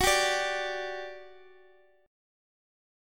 G5/F# chord {x x x 11 8 10} chord